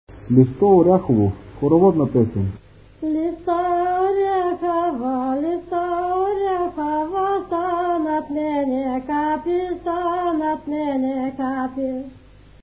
музикална класификация Песен
форма Четириредична
размер Две четвърти
фактура Едногласна
начин на изпълнение Солово изпълнение на песен
битова функция На хоро
фолклорна област Югоизточна България (Източна Тракия с Подбалкана и Средна гора)
място на записа Горно Изворово
начин на записване Магнетофонна лента